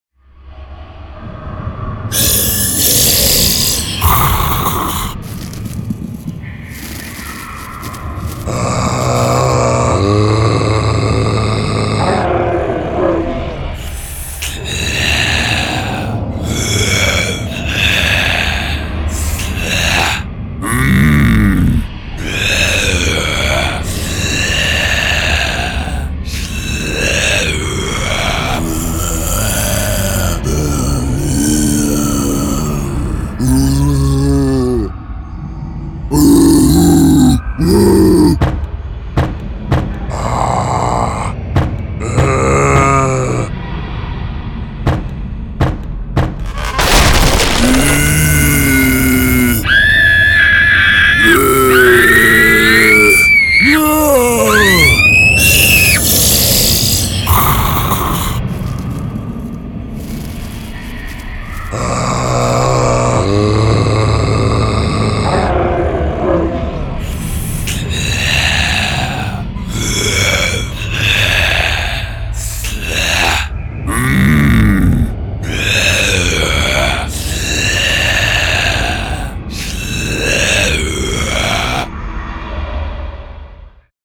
音效-恐怖电影丧失僵尸音效
觉醒，人脑上的盛宴，剥夺人类的肉体，寻找亡灵的僵尸猎人，宝藏的逼近，成堆的僵尸从大到小构成，低吼，an吟，喃喃和洗牌。
格鲁姆布林克，萨那林克，咆哮，嘶嘶声，格罗林克和G吟。有狂暴的咆哮声，饥饿的Gro吟声，Screechinq ans吟声和愤怒的咆哮声。